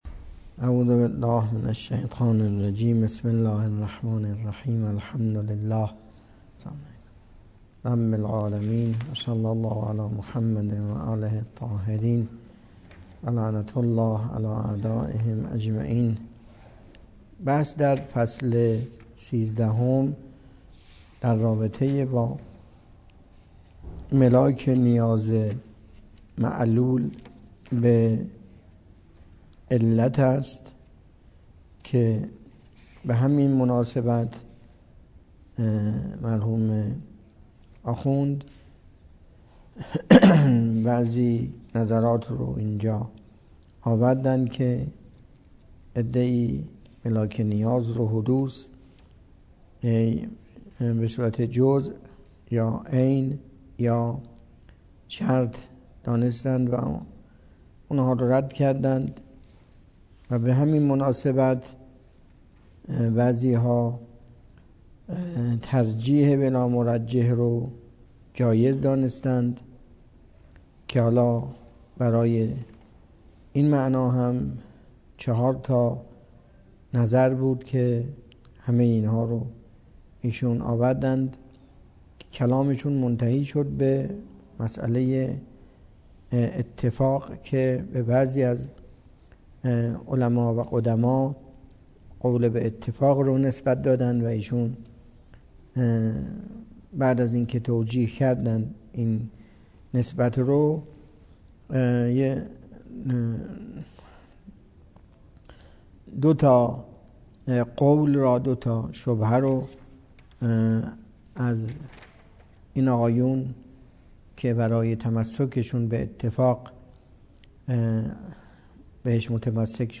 درس فلسفه اسفار اربعه
سخنرانی